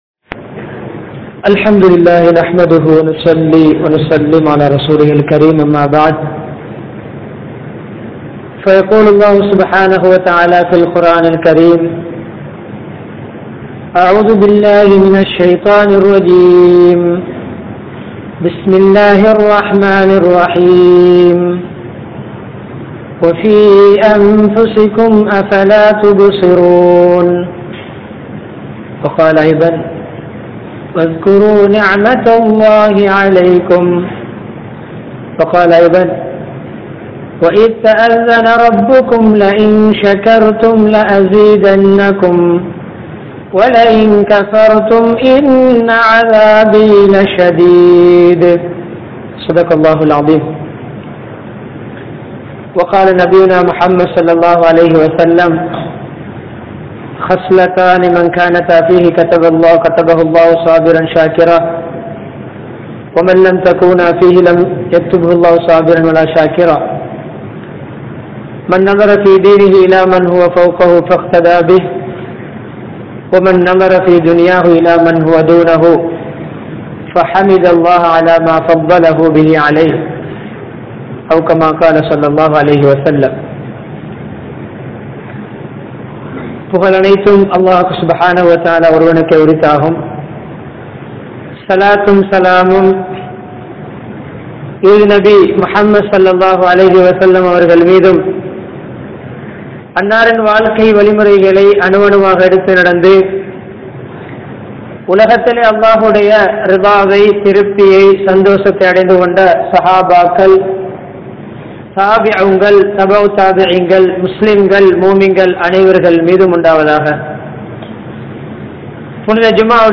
Amalhalil Poatti Poaduvoam (அமல்களில் போட்டி போடுவோம்) | Audio Bayans | All Ceylon Muslim Youth Community | Addalaichenai
Majmaulkareeb Jumuah Masjith